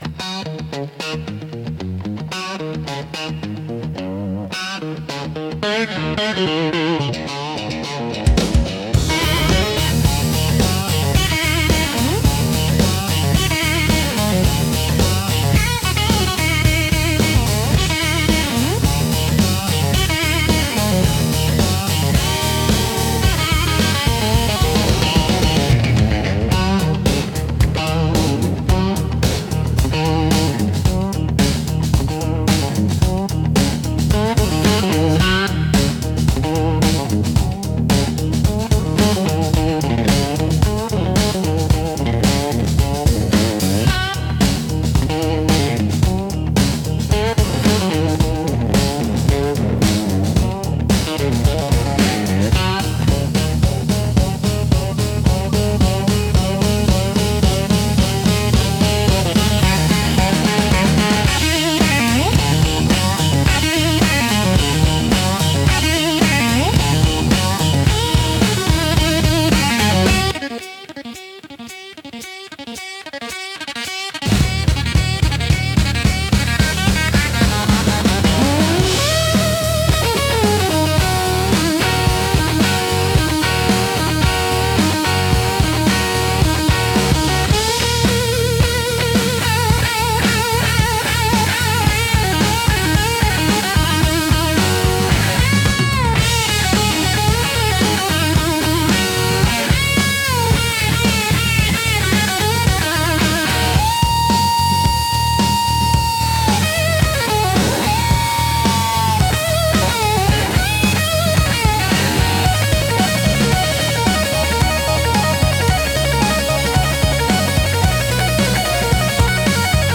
Instrumental - Midnight at the Neon Crossroads